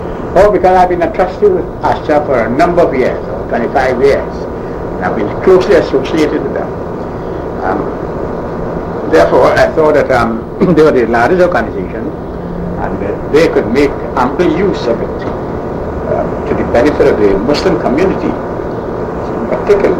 l audio cassette